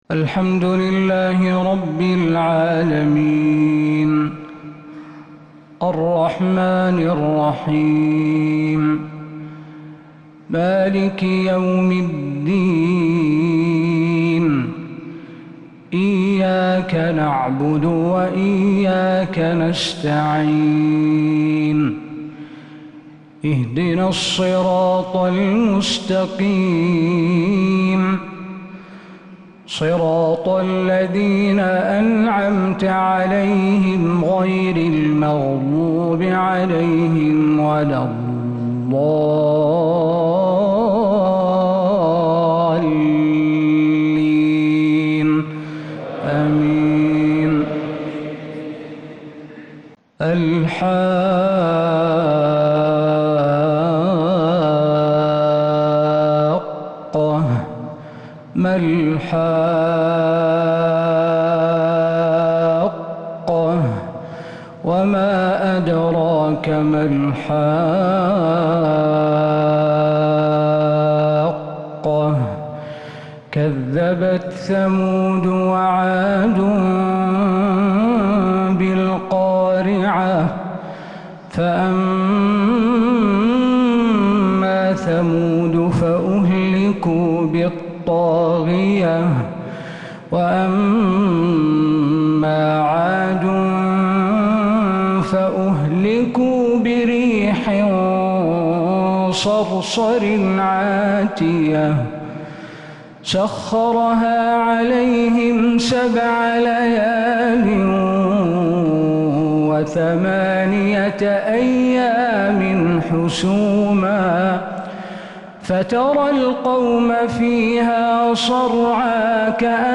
فجر السبت 15 صفر 1447هـ | سورة الحاقة كاملة | Fajr prayer from surah al-Haqqah 9-8-2025 > 1447 🕌 > الفروض - تلاوات الحرمين